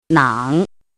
怎么读
nǎng
nang3.mp3